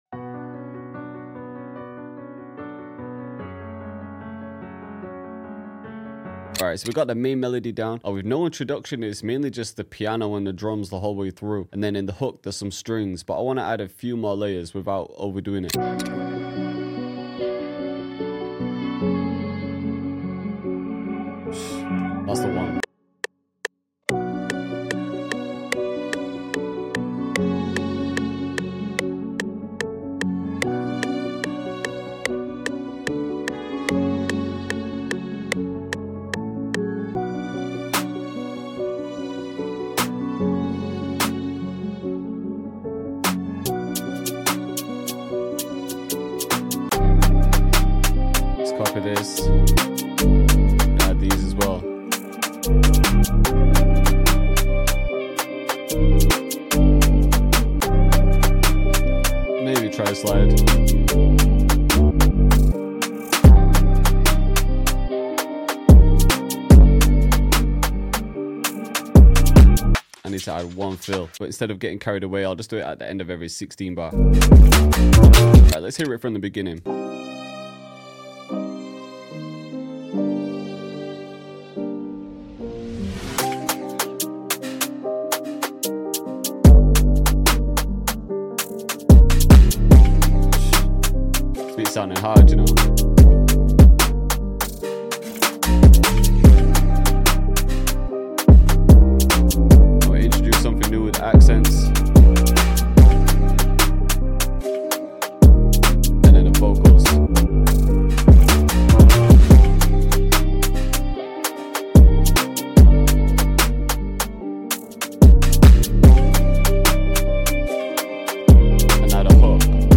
How to make uk drill sound effects free download